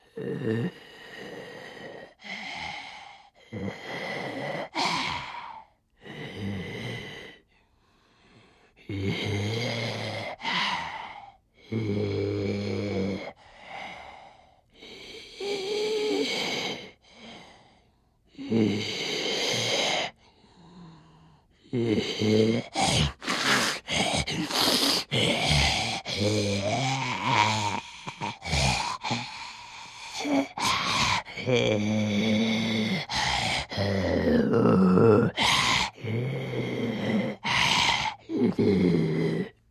MaleBreathsLabored AZ174801
Male Breaths, Labored Hard Inhale And Choking Sounds.